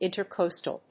音節ìnter・cóstal 発音記号・読み方
/ˌɪn.təˈkɒs.təl(米国英語)/